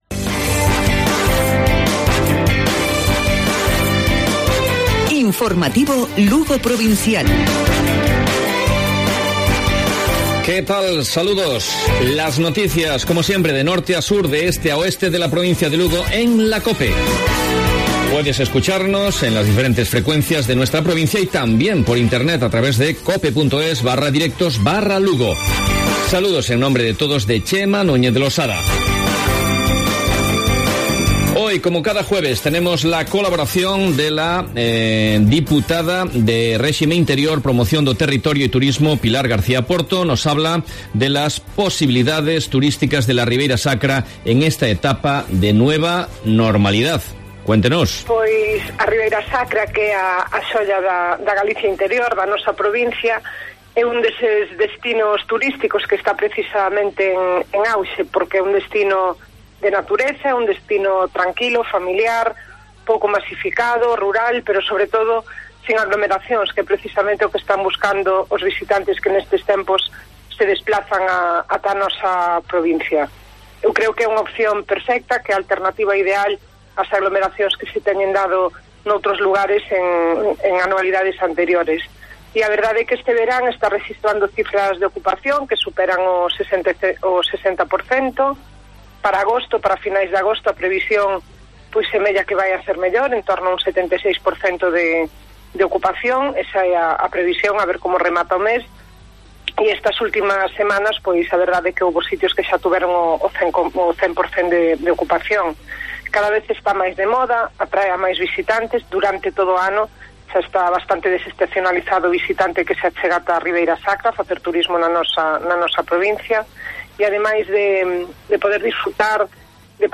Informativo Provincial Cope Lugo. Jueves, 20 de agosto 12:50-13:00 horas